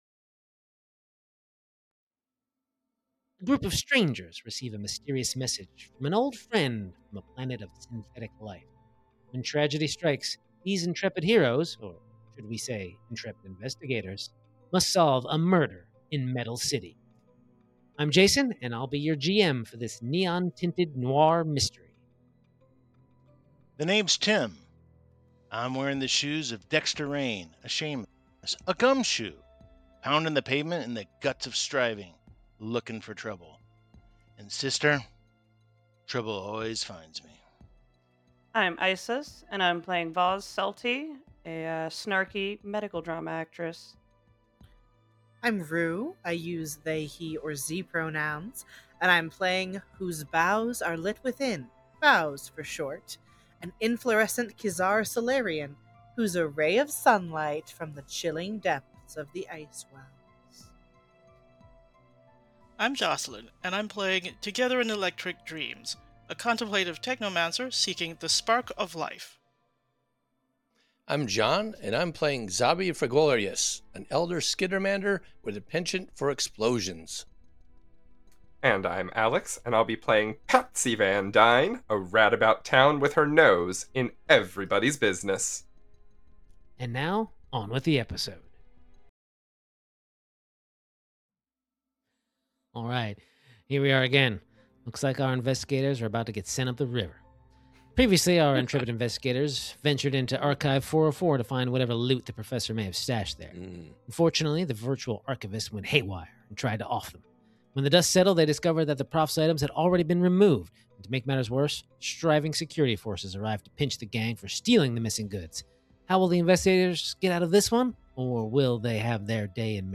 Starfinder 2nd Edition Actual Play Podcast